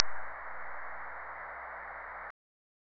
Rig here is 1 kw to 2 - M2-2M5WL's. 100 feet of 7/8" and preamp in the shack.